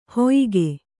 ♪ hoyige